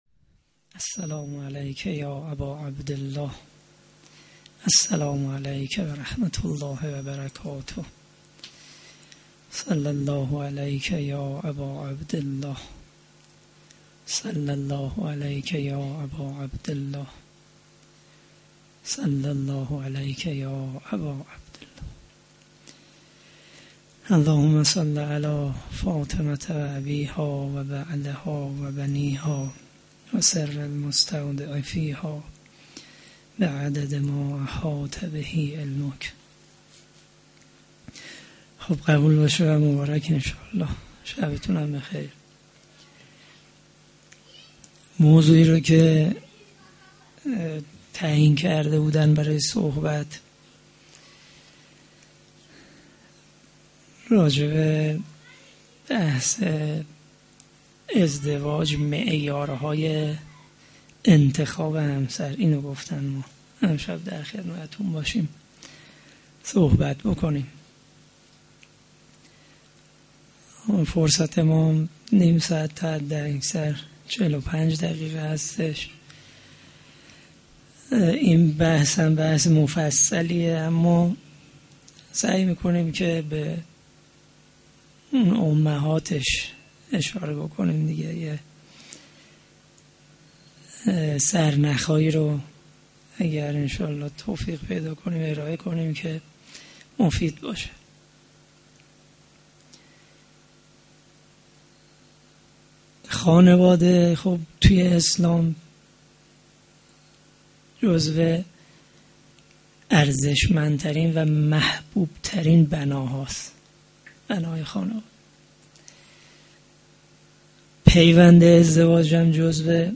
veladate-hazrate-zahra-s-93-sokhanrani.mp3